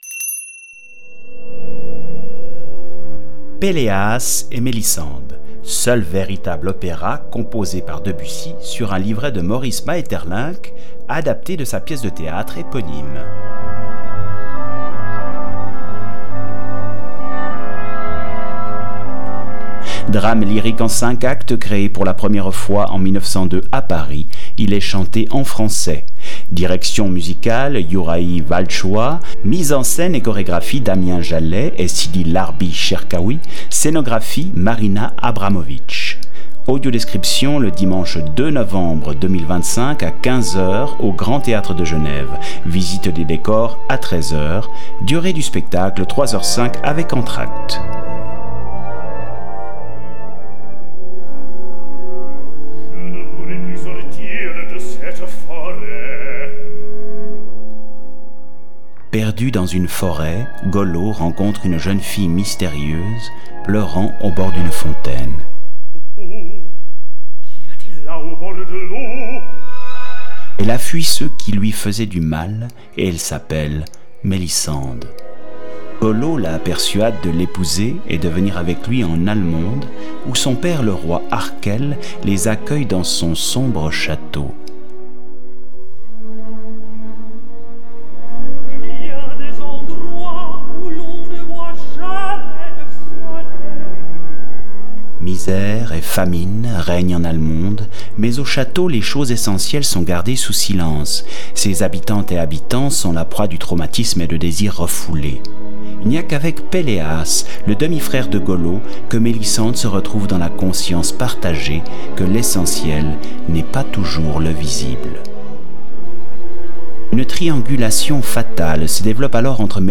Opéra
Audiodescription
Bande annonce